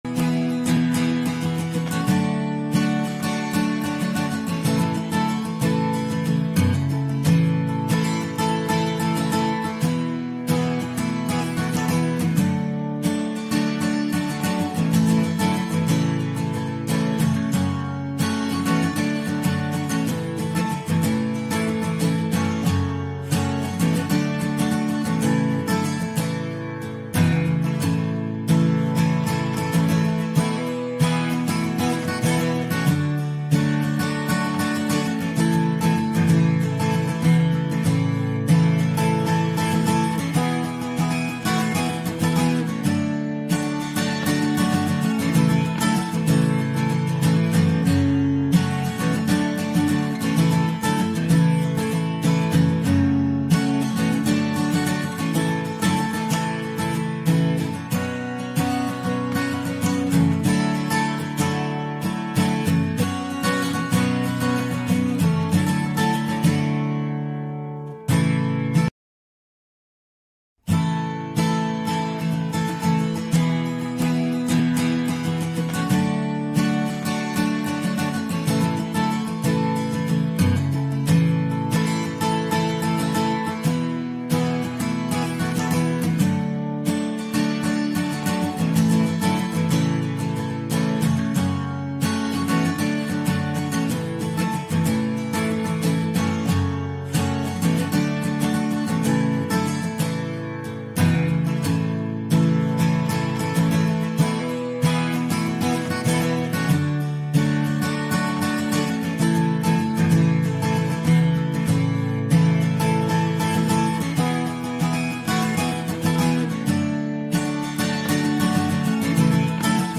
special guest, actor, author, director and producer Kristoffer Polaha